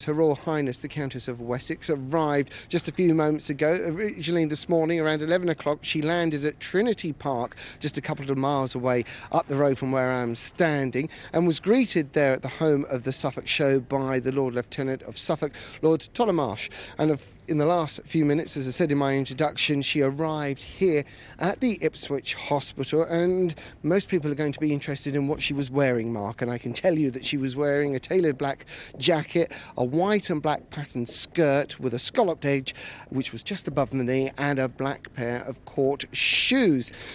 The Countess of Wessex has arrived at Ipswich Hospital to offically open the Heart Centre.